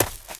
High Quality Footsteps
STEPS Leaves, Run 02.wav